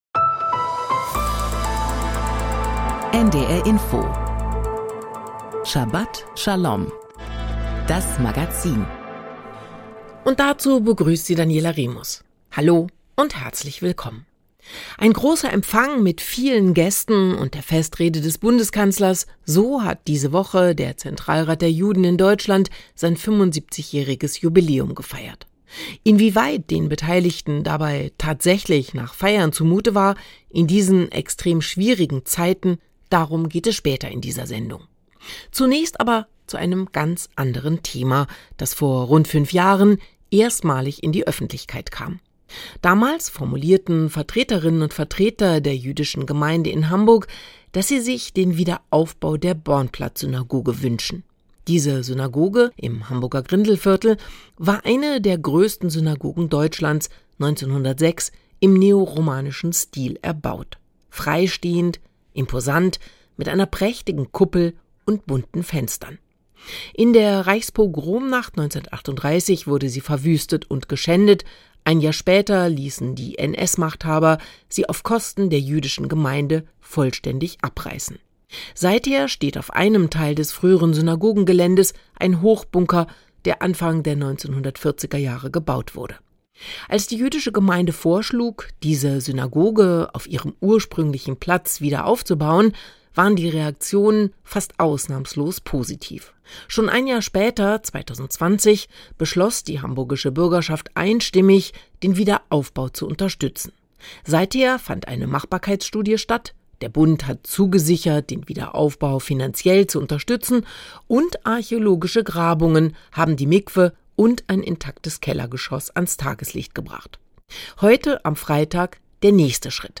Beschreibung vor 6 Monaten Die Themen der Sendung: Ergebnis des Architekturwettbewerbs: Wie soll der Wiederaufbau der Hamburger Bornplatzsynagoge aussehen? Ein Gespräch
Ein Stimmungsbericht